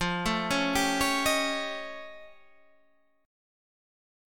Listen to F+9 strummed